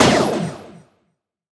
laser_02.wav